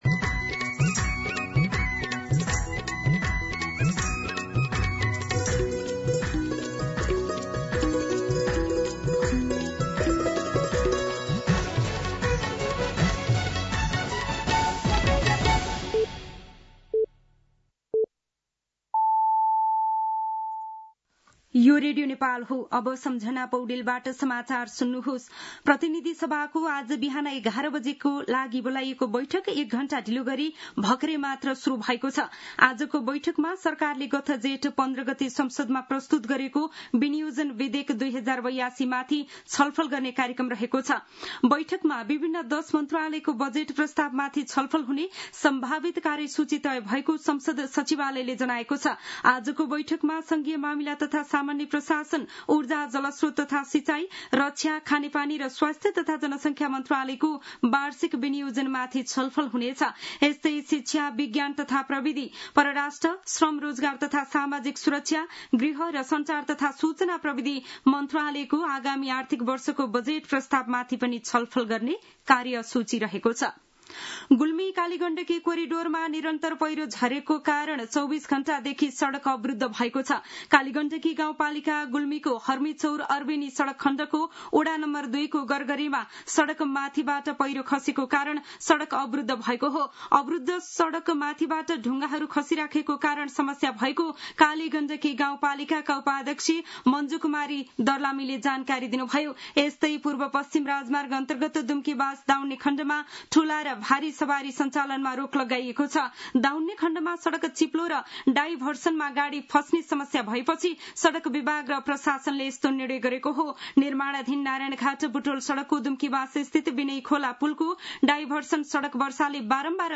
मध्यान्ह १२ बजेको नेपाली समाचार : ७ असार , २०८२